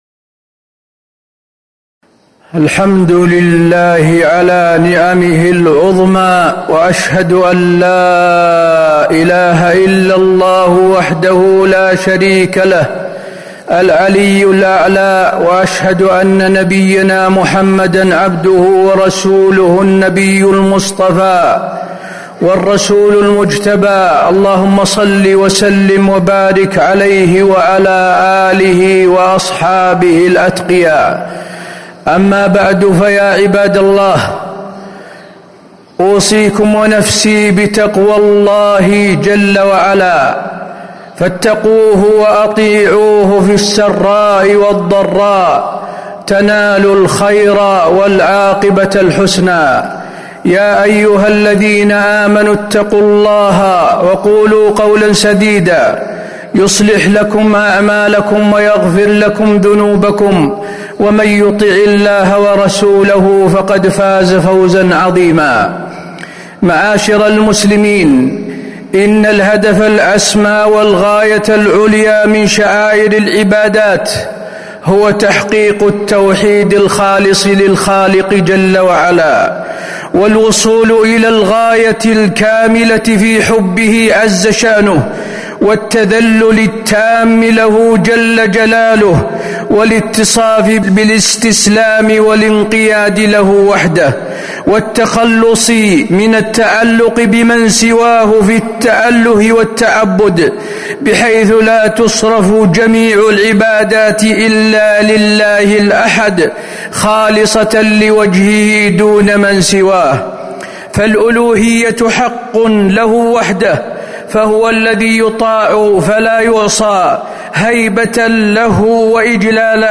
تاريخ النشر ٥ رمضان ١٤٤٥ هـ المكان: المسجد النبوي الشيخ: فضيلة الشيخ د. حسين بن عبدالعزيز آل الشيخ فضيلة الشيخ د. حسين بن عبدالعزيز آل الشيخ المقاصد العظيمة من عبادات رمضان